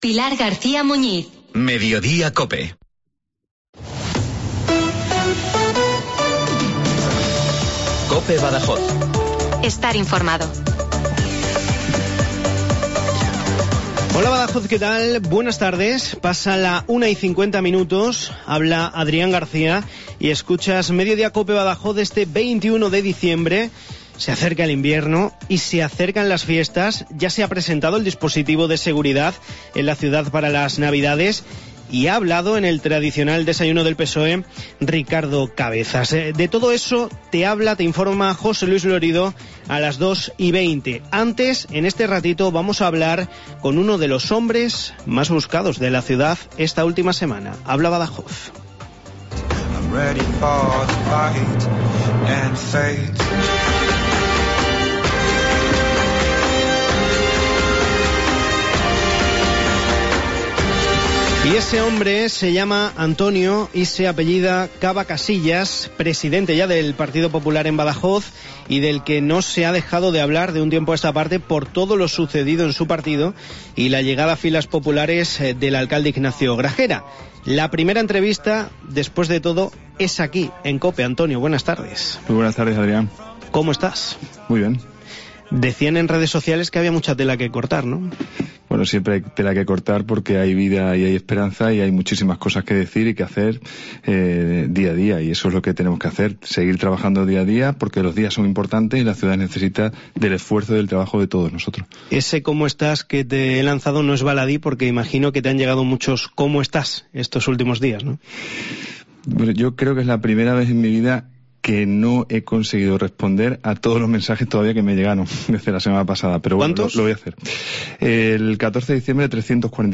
AUDIO: El presidente del PP en Badajoz, concedió su primera entrevista tras el 'fichaje' de Gragera y no confirmó que fuese a formar parte de la...